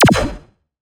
Misc Synth stab 01.wav